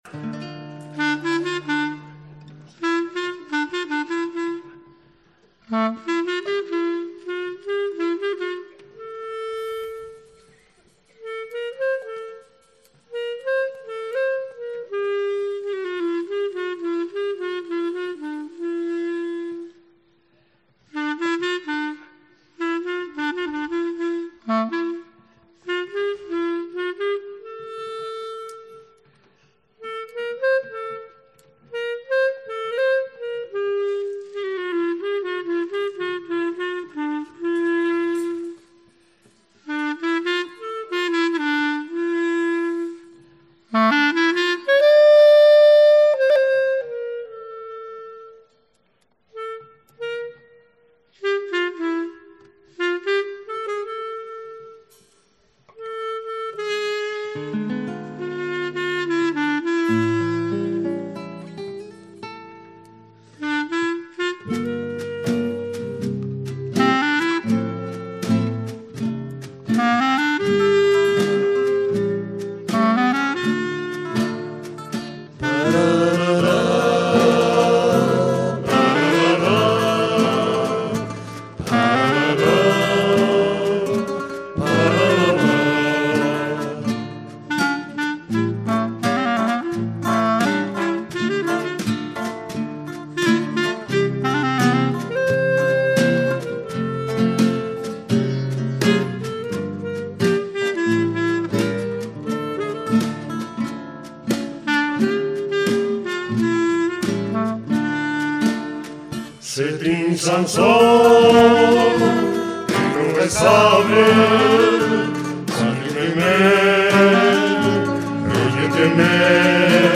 CONCIERTO 2014, en directo